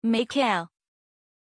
Aussprache von Miquel
pronunciation-miquel-zh.mp3